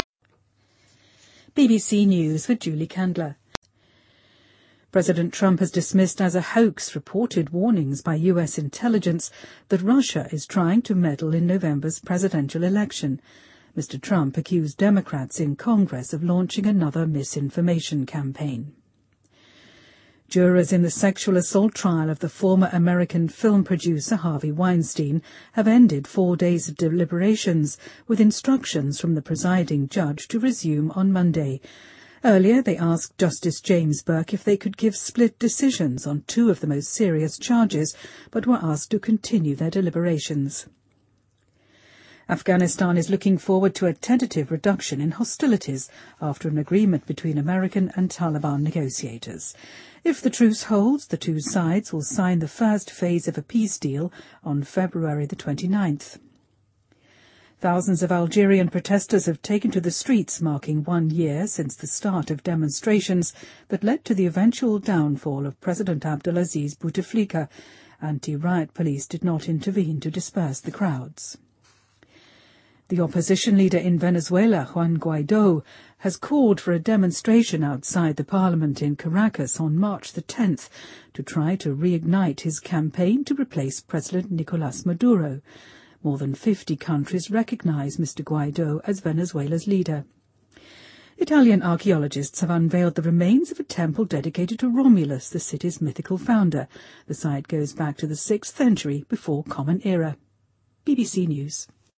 News
英音听力讲解:美情报部门警告俄罗斯试图干预大选